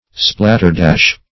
Splatterdash \Splat"ter*dash`\, n.